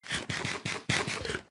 Оригинальные игровые эффекты помогут добавить атмосферу Майнкрафта в ваши видео.
Minecraft — звуки поедания еды